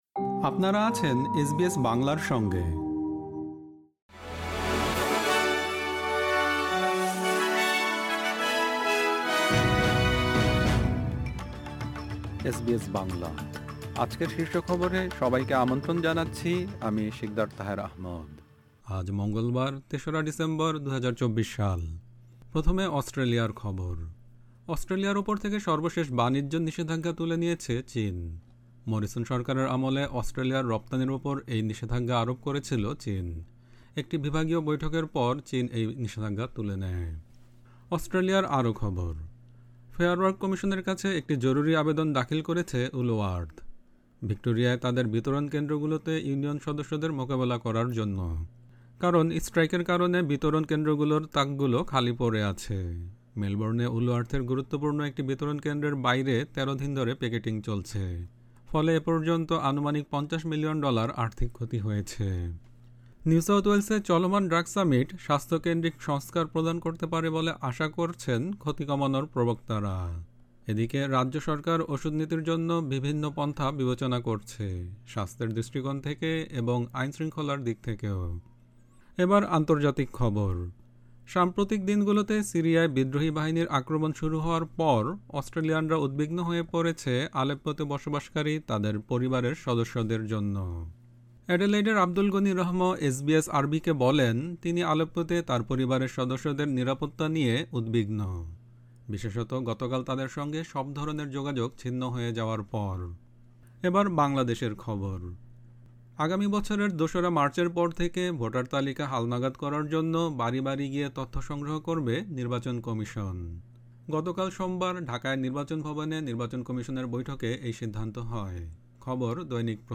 এসবিএস বাংলা শীর্ষ খবর: ৩ ডিসেম্বর, ২০২৪